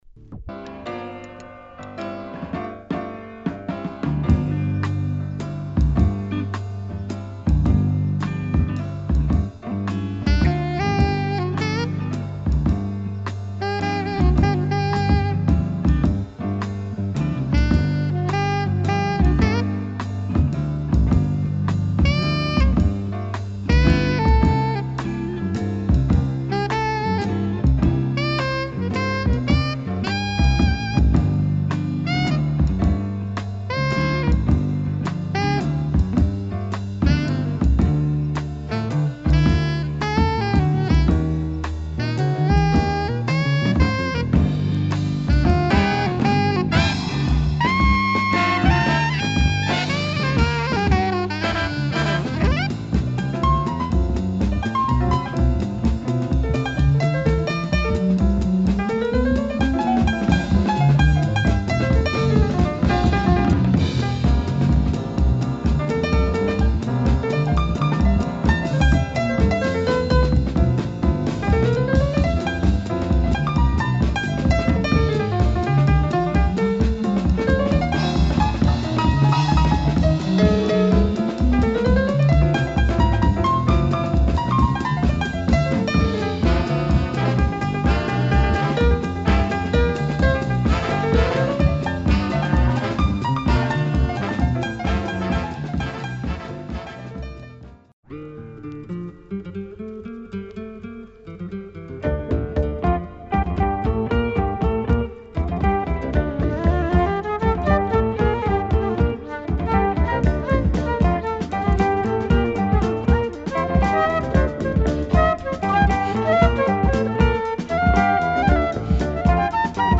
Great prog jazz sounds with flute and breaks.